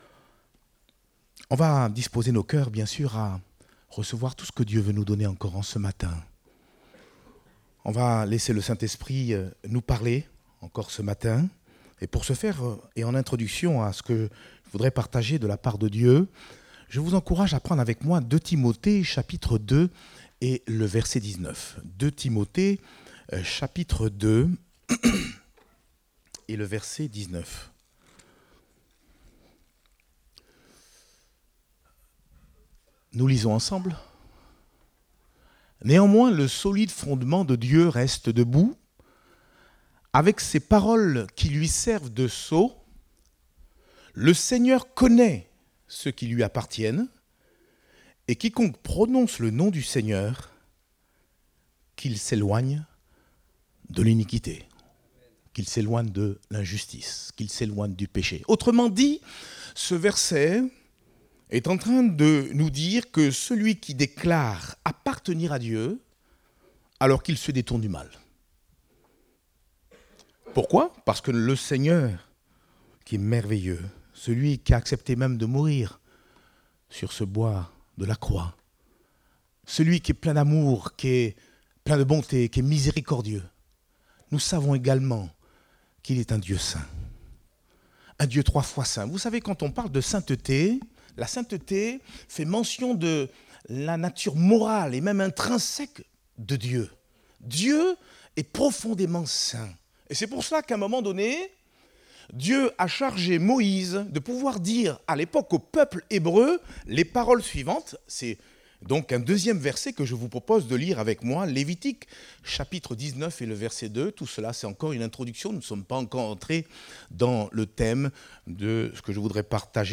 Date : 3 décembre 2023 (Culte Dominical)